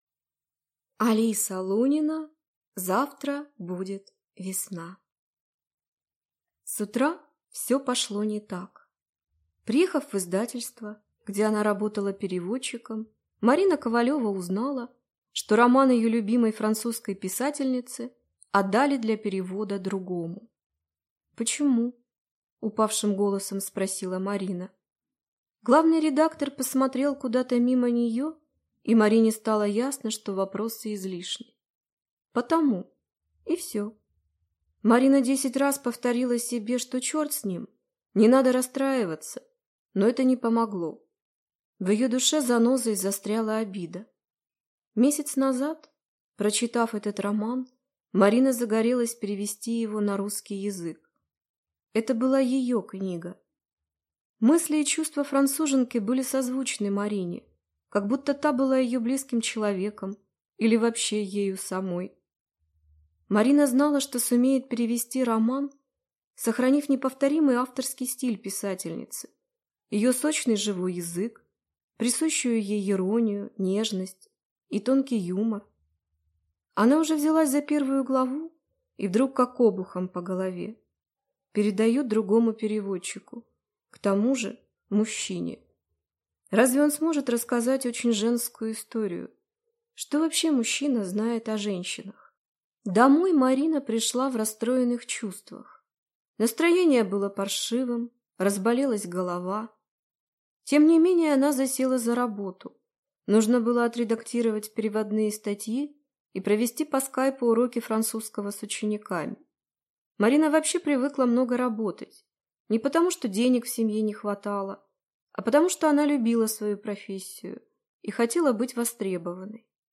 Аудиокнига Завтра будет весна | Библиотека аудиокниг